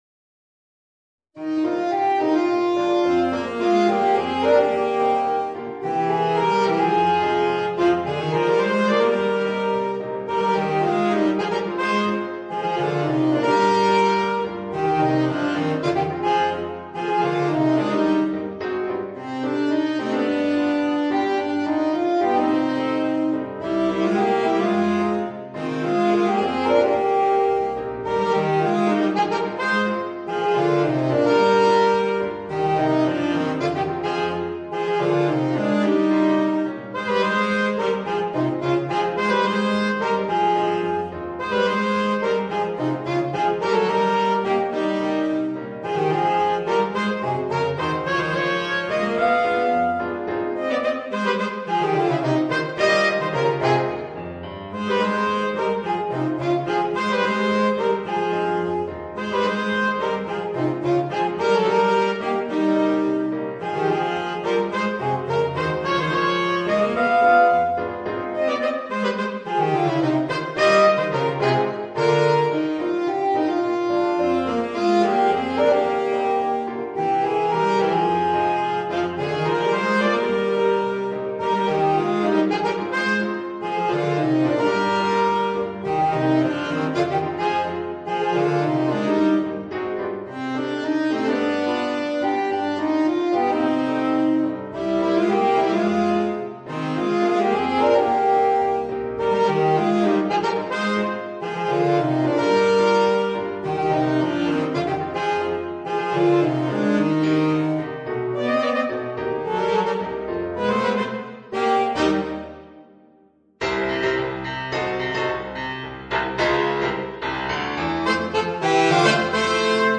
Voicing: Alto Saxophone, Tenor Saxophone and Piano